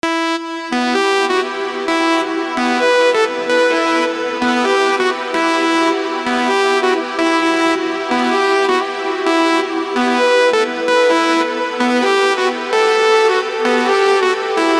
audio_examples/legato_ex_2.mp3 · adlb/Audialab_EDM_Elements at 0409b503a1d203ef3dfe4e7bff74b68cd93ba52e
legato_ex_2.mp3